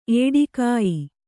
♪ ēḍikāyi